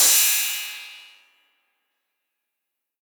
808CY_4_TapeSat_ST.wav